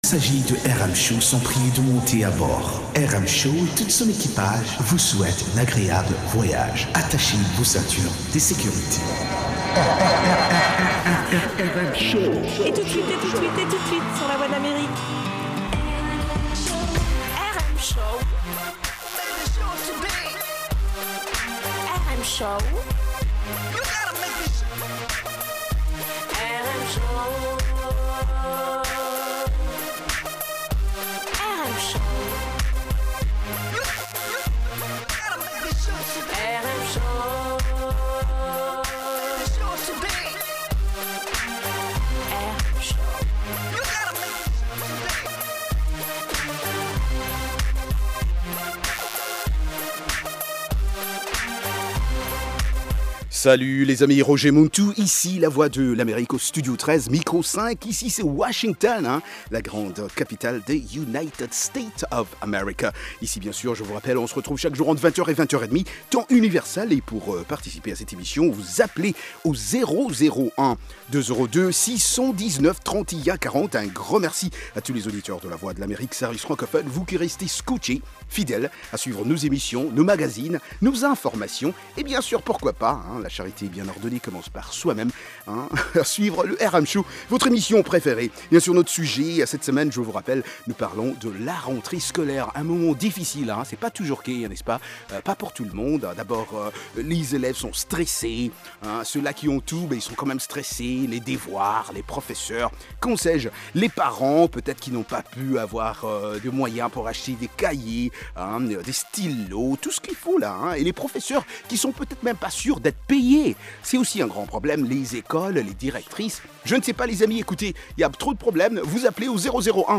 reportages et interviews